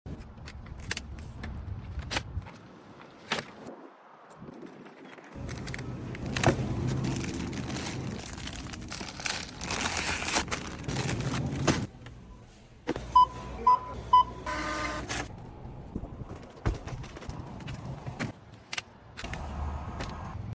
Grocery restock with the handiest sound effects free download